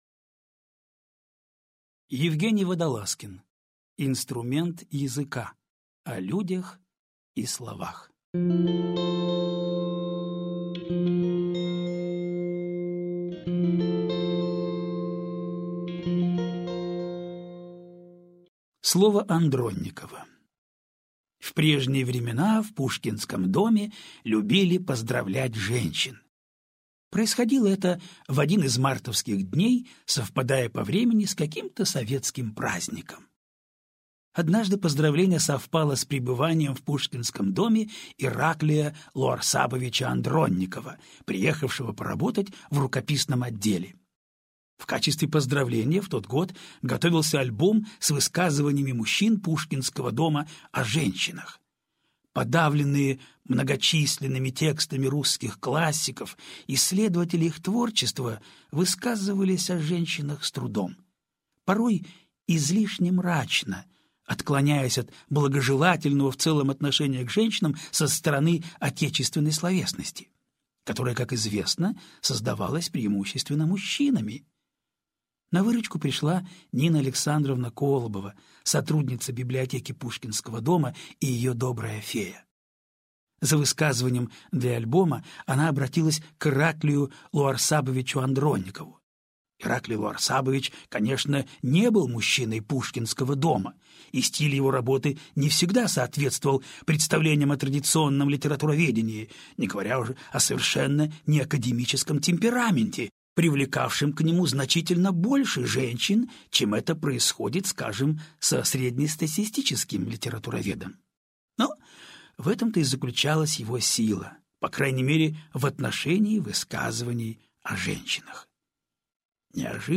Аудиокнига Инструмент языка. О людях и словах | Библиотека аудиокниг